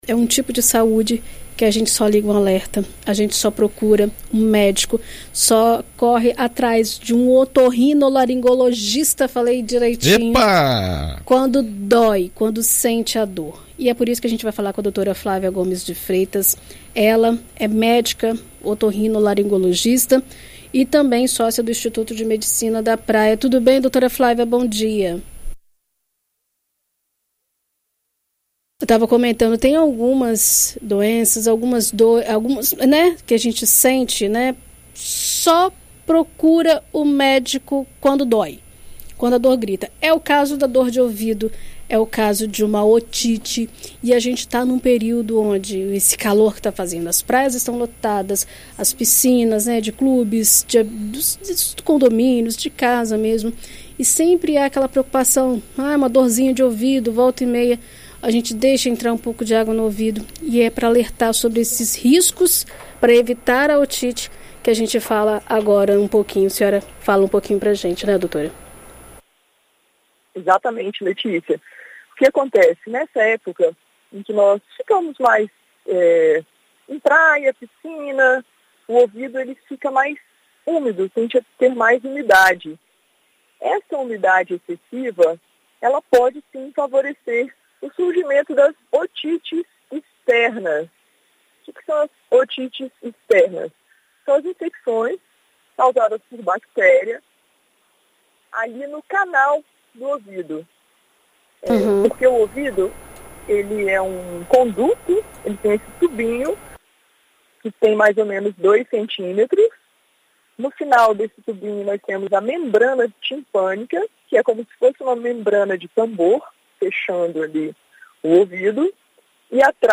Em entrevista a BandNews FM Espírito Santo nesta terça-feira